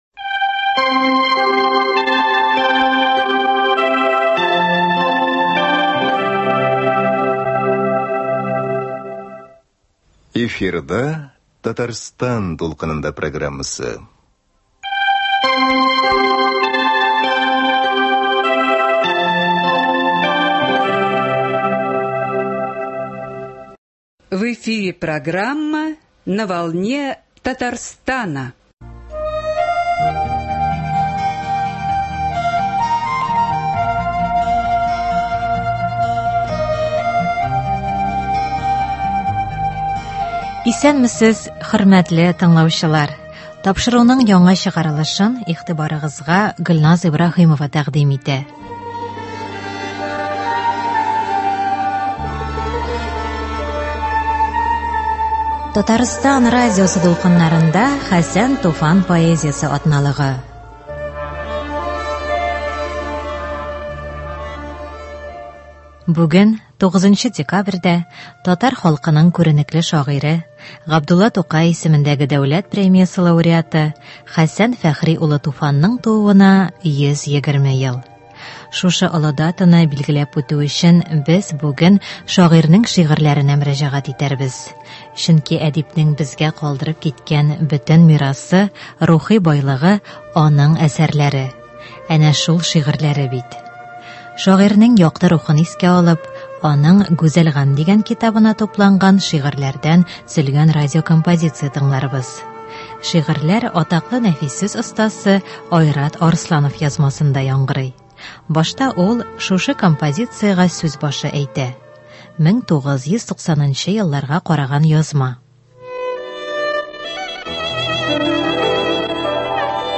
Шагыйрьнең якты рухын искә алып, аның “Гүзәл гамь” дигән китабына тупланган шигырьләрдән төзелгән радиокомпозиция тыңларбыз.
Башта ул шушы композициягә сүз башы әйтә. 1990 елларга караган язма.